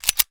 gun_pistol_cock_05.wav